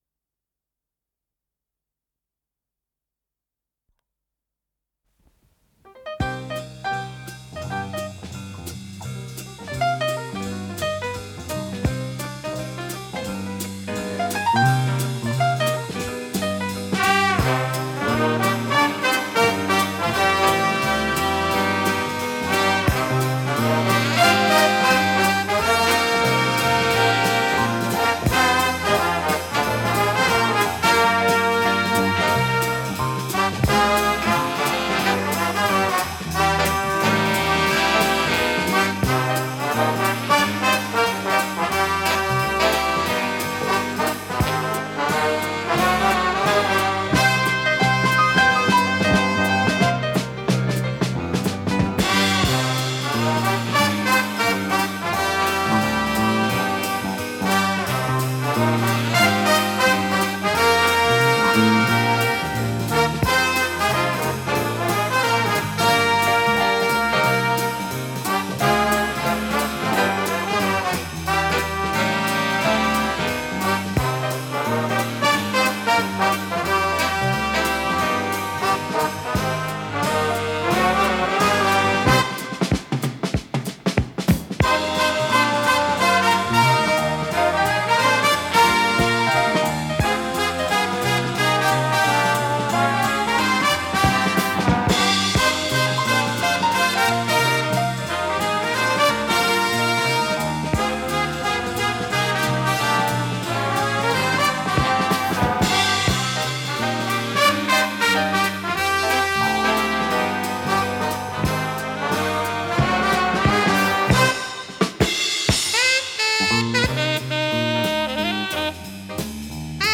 ПодзаголовокПьеса для эстрадного оркестра, до минор
Скорость ленты38 см/с
ВариантДубль моно